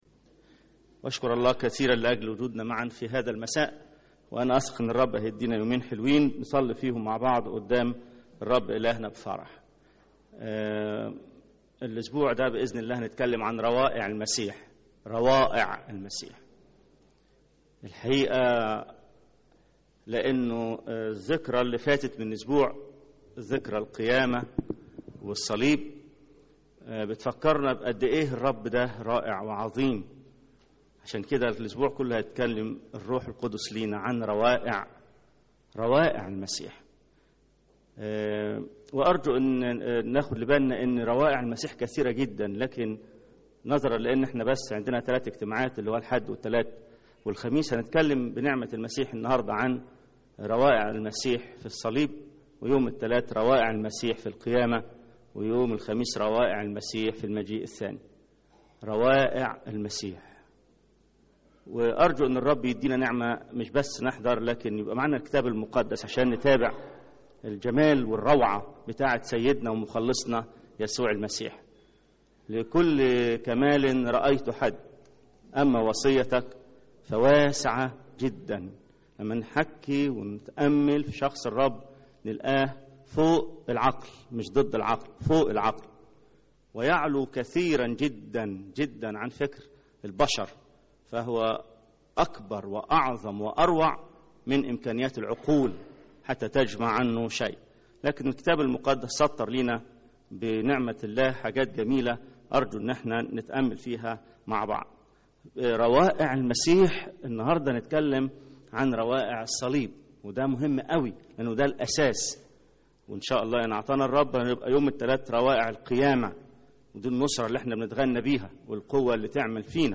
ثلاث عظات عن روائع المسيح: في الصليب، في القيامة، في المجيء الثاني … العظة الأولى – روعة المسيح في الصليب